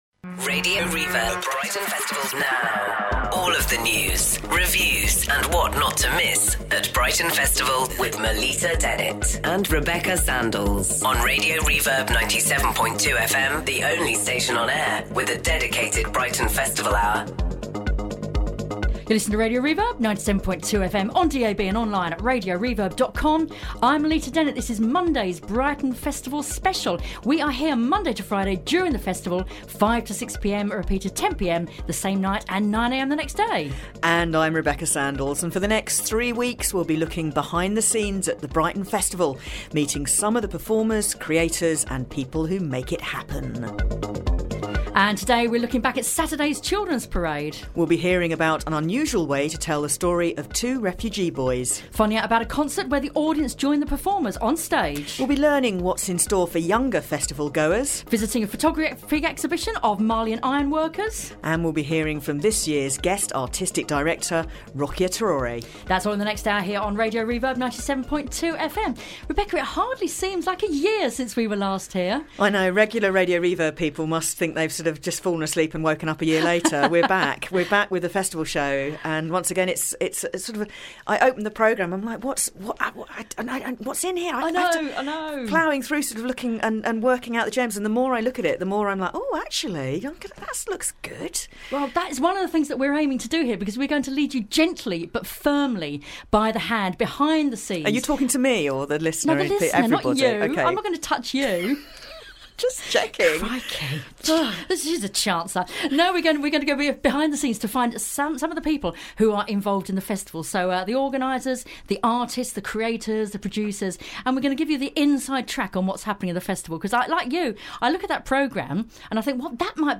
An interview with Guest Director Rokia Traore and a look at the Children's events at the festival.